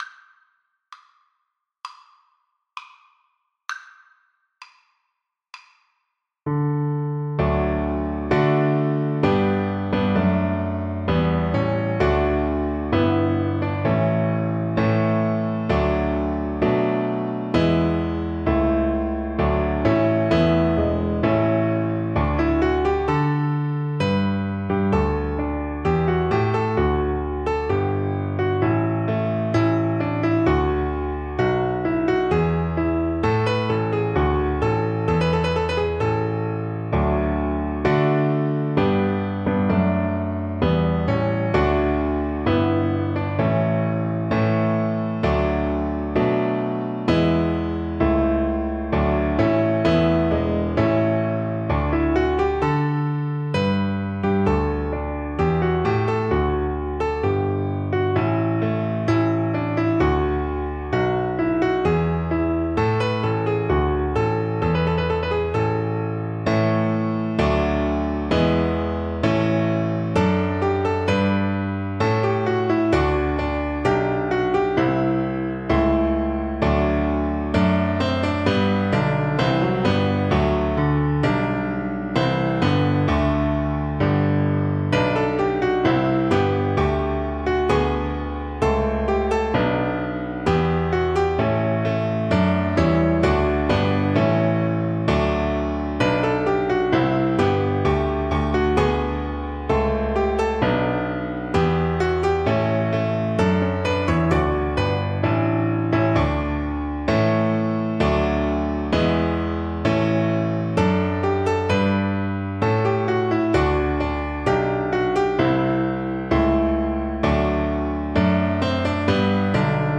Cello
D major (Sounding Pitch) (View more D major Music for Cello )
Allegro moderato (View more music marked Allegro)
4/4 (View more 4/4 Music)
Classical (View more Classical Cello Music)